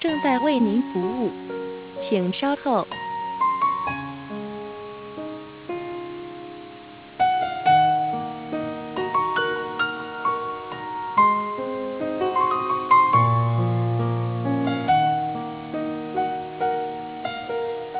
保持音.wav